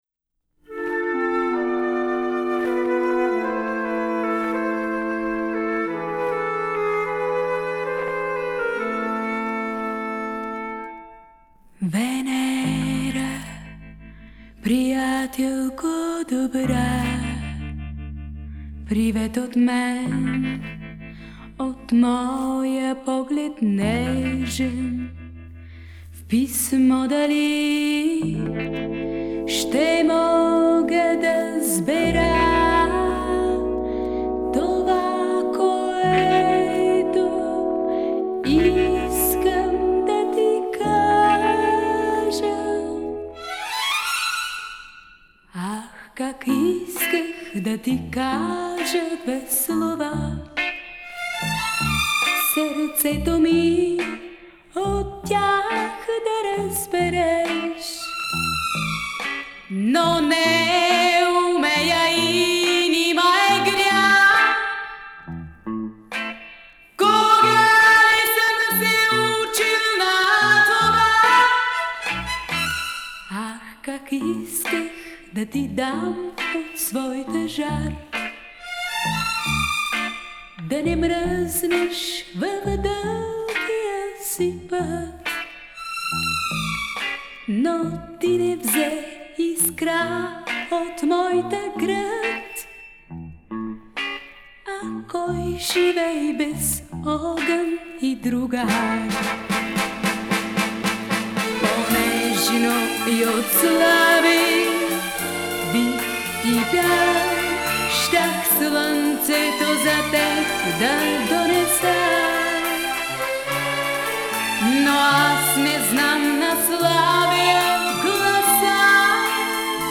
а эстрадного оркестра со струнными